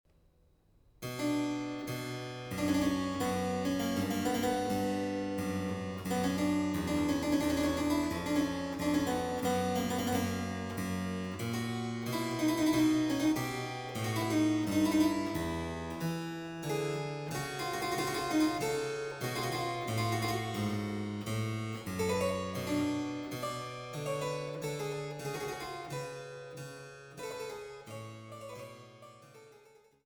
Vivement